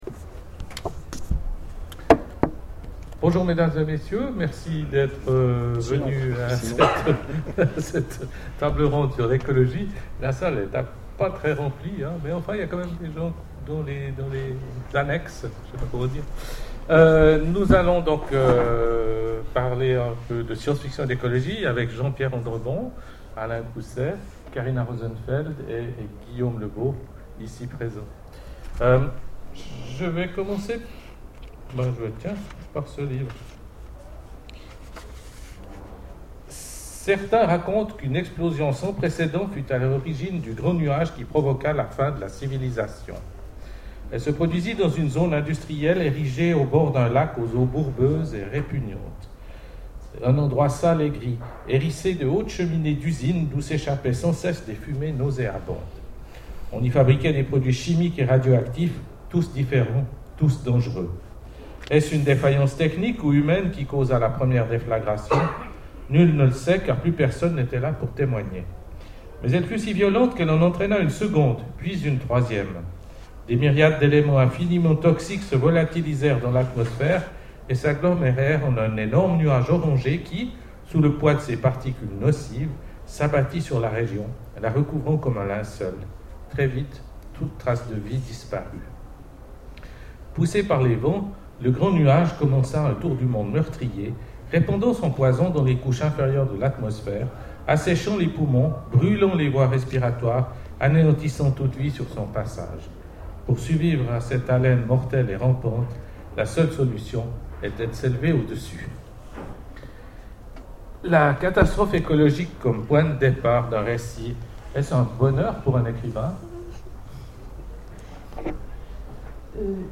Imaginales 2012 : Conférence C'est notre planète qu'on assassine
Conférence